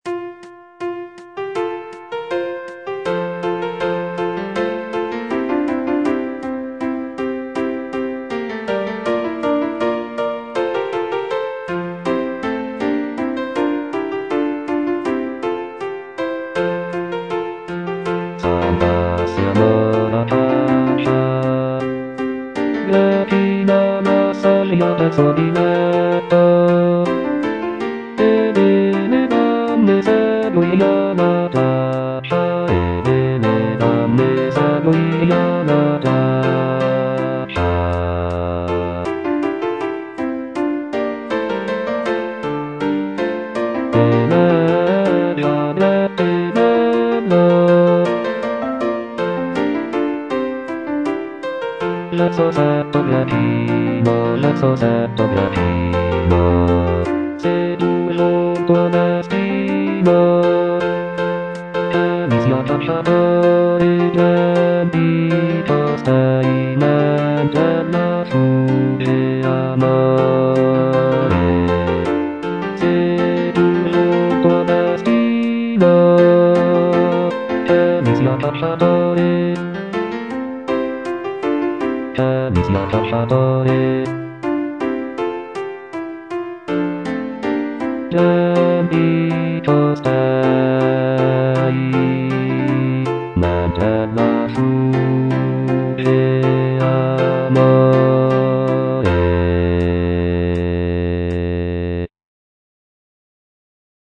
Bass (Voice with metronome, piano)
madrigal